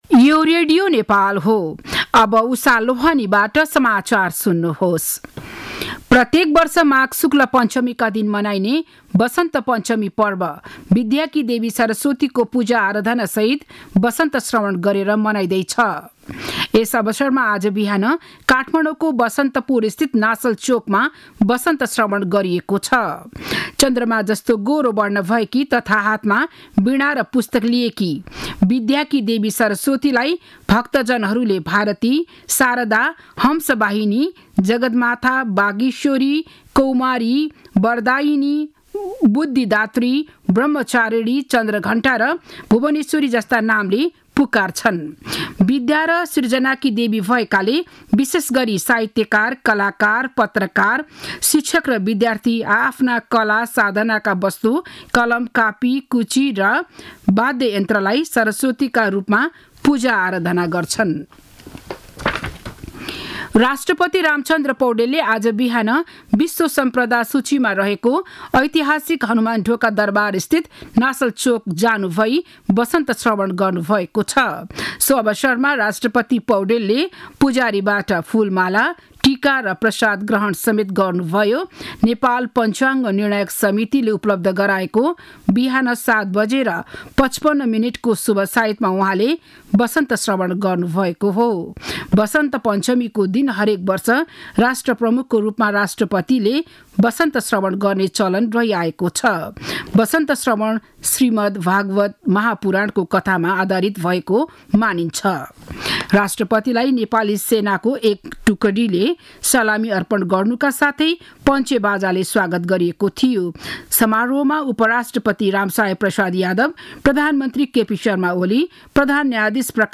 बिहान ११ बजेको नेपाली समाचार : २२ माघ , २०८१
11-am-news-1-1.mp3